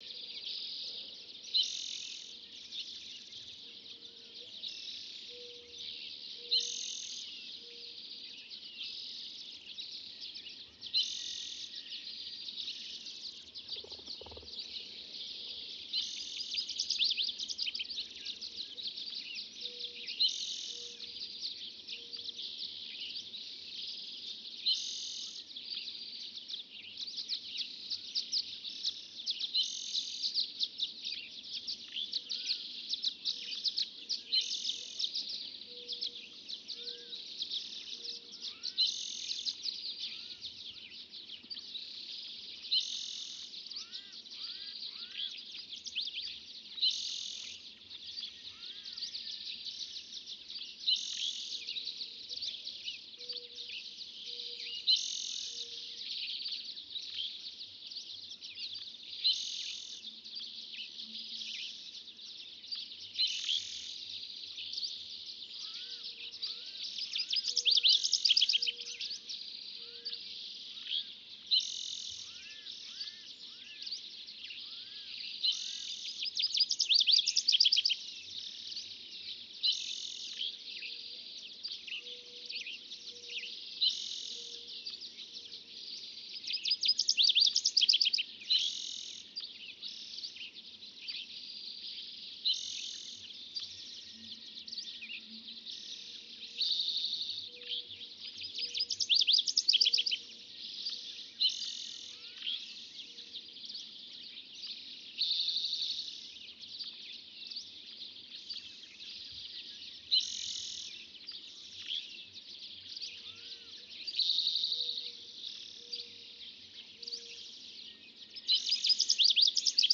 Canyon dawn chorus active diverse BINAURAL
Canyon-dawn-chorus-active-diverse-BINAURAL.wav